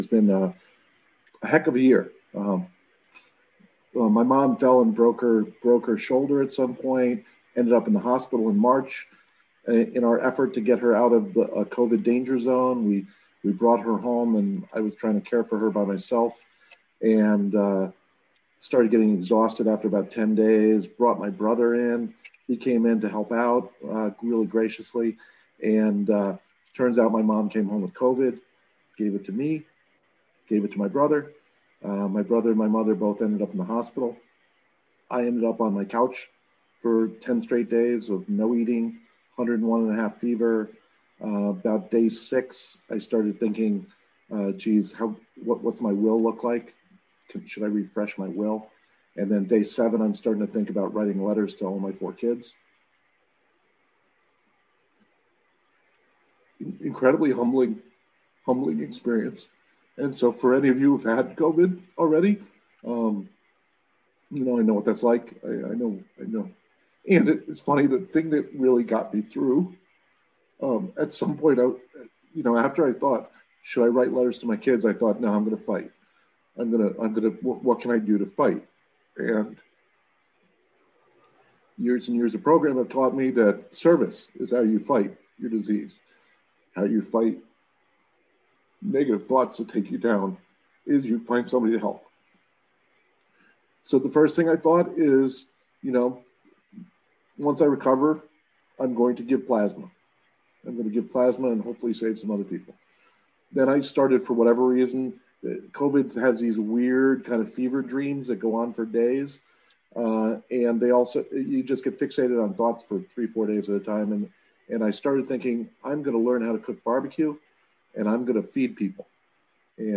46th San Fernando Valley Alcoholics Anonymous UnConventional